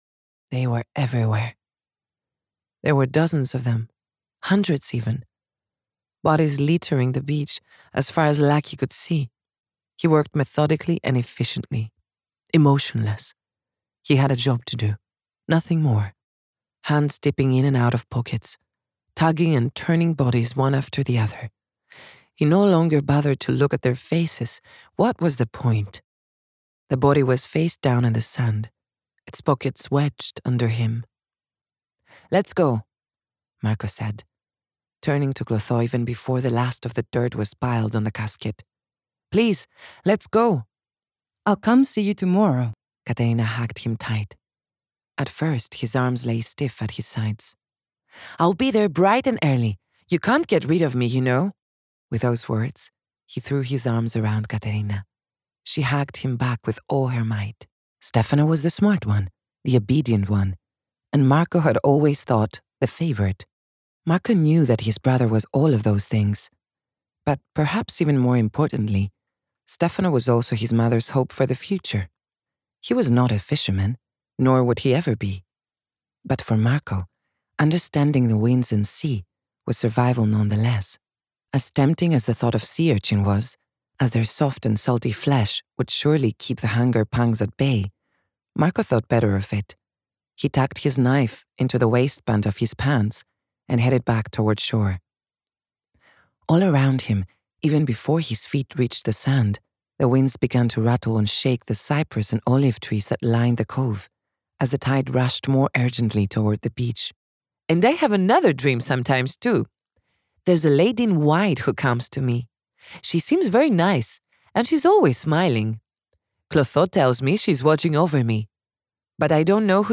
Female
Approachable, Assured, Authoritative, Character, Children, Confident, Conversational, Corporate, Deep, Energetic, Engaging, Friendly, Natural, Reassuring, Smooth, Versatile, Warm
EN Commercial Demo 2025.mp3
Microphone: MKH 416 Sennheiser Shotgun
Audio equipment: Professionally sound-proofed home studio room, RME Fireface UCX II, Audio-Technica ATH M50x Headphones, Kali Audio studio monitors